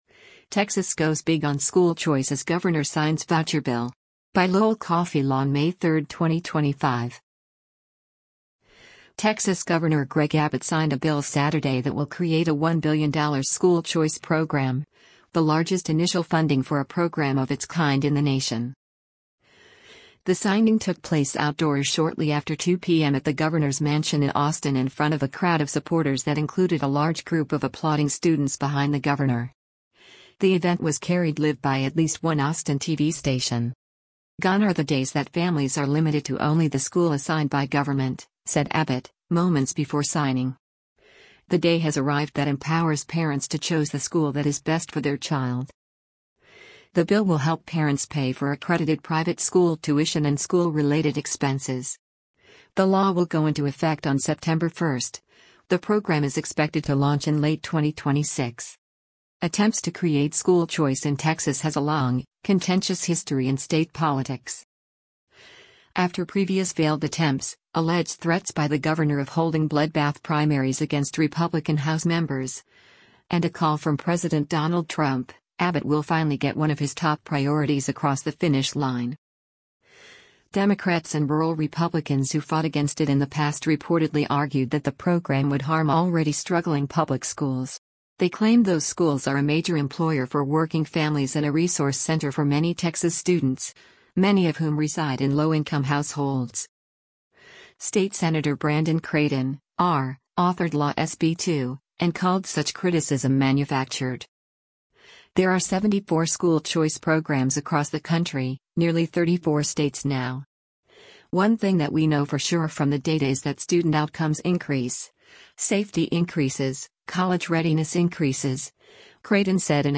The signing took place outdoors shortly after 2:00 p.m. at the governor’s mansion in Austin in front of a crowd of supporters that included a large group of applauding students behind the governor.